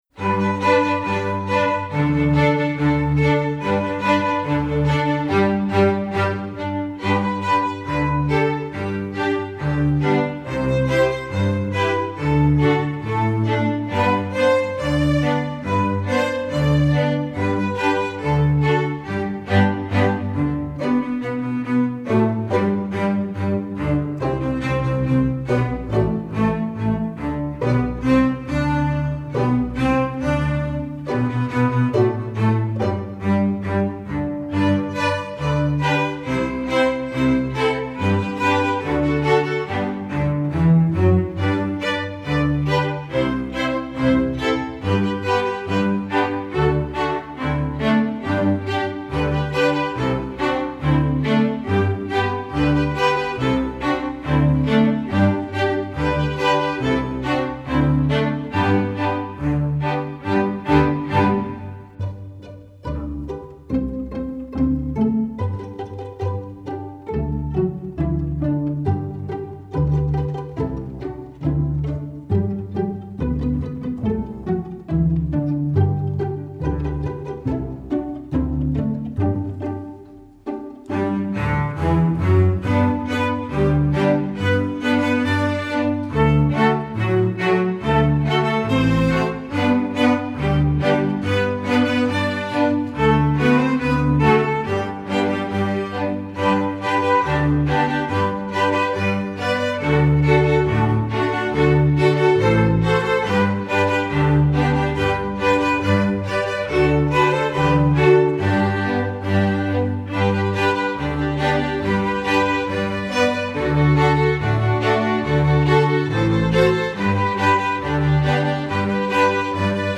folk, american